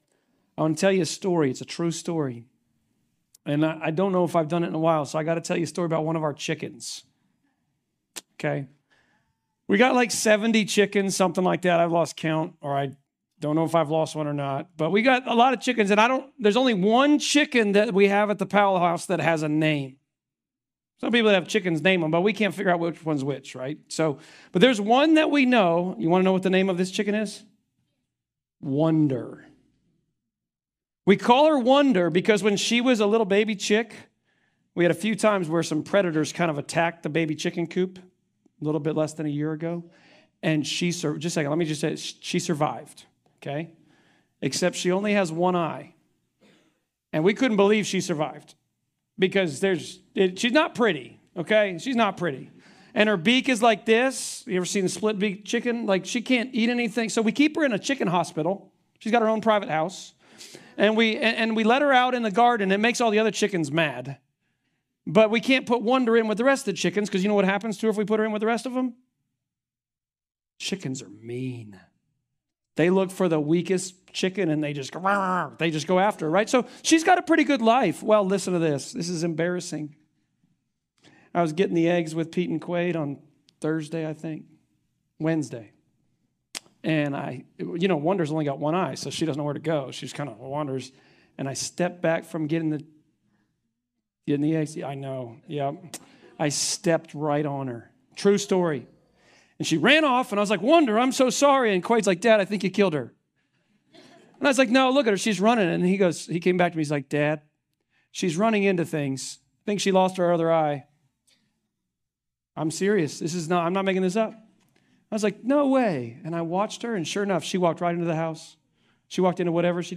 Luke 7.1-23 Service Type: Sermons « Rooted in our Community The reality of PROMISE.